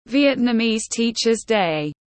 Ngày nhà giáo Việt Nam tiếng anh gọi là Vietnamese Teacher’s Day, phiên âm tiếng anh đọc là /ˌvjɛtnəˈmiːz ˈtiːʧəz deɪ/
Vietnamese Teacher’s Day /ˌvjɛtnəˈmiːz ˈtiːʧəz deɪ/
Vietnamese-Teachers-Day-.mp3